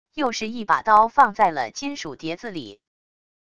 又是一把刀放在了金属碟子里wav音频